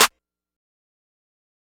Metro Clp&Snr2.wav